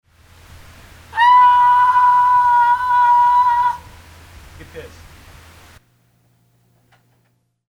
the saviors of jangly noodly punk rock.